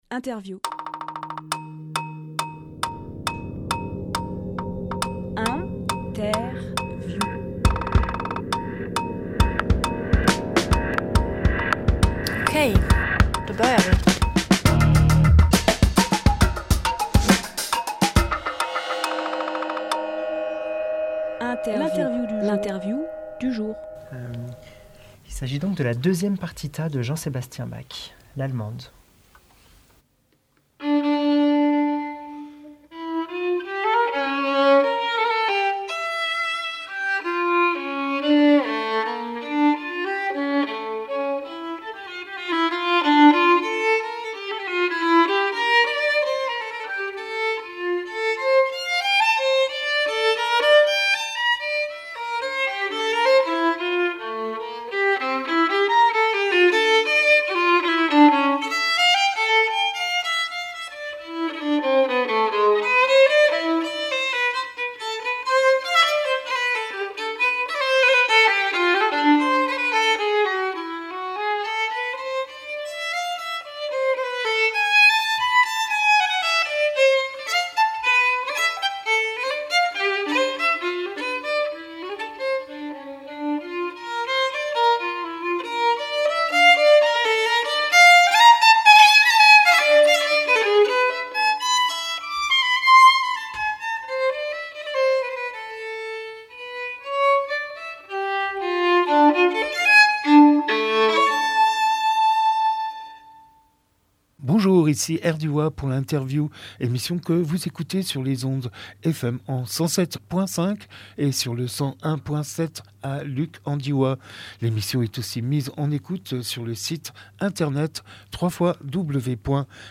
Emission - Interview Le Goût du La Publié le 5 mai 2023 Partager sur…
04.05.23 Lieu : Studio RDWA Durée